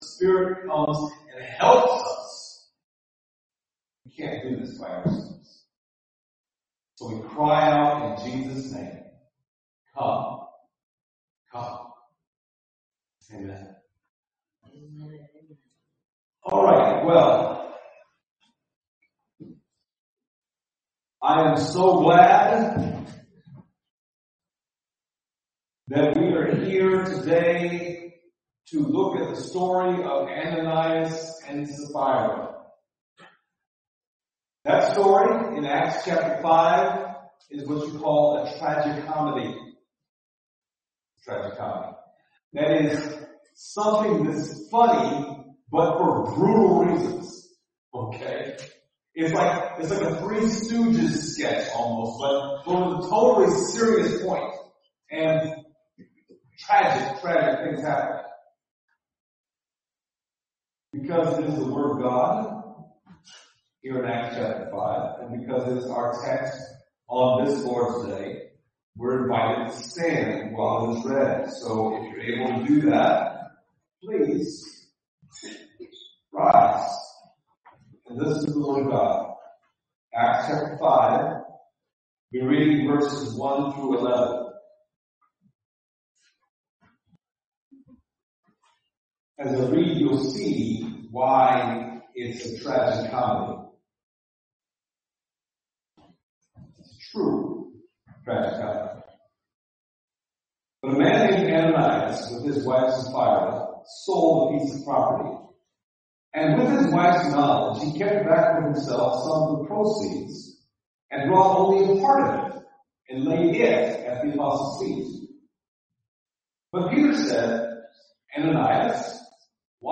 The Acts of the Holy Spirit Passage: Acts 5:1-11 Service Type: Sunday Morning « Christian Communism?